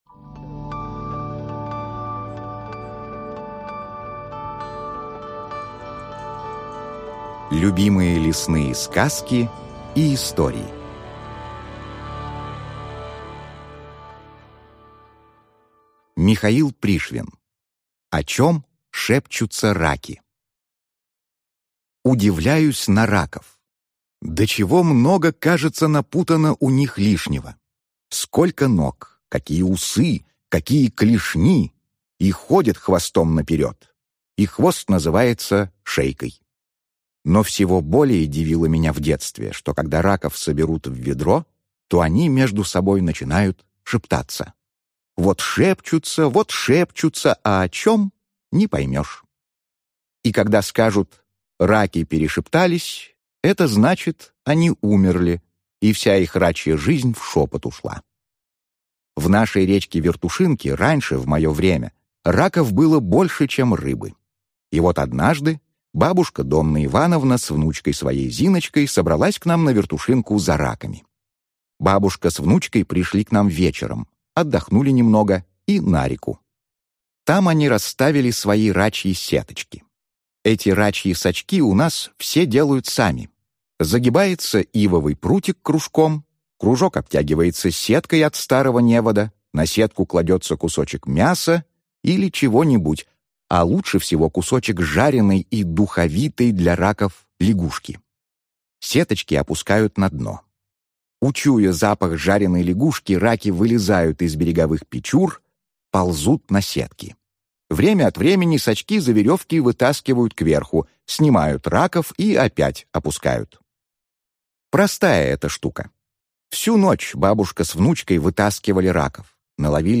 Аудиокнига Лесные сказки | Библиотека аудиокниг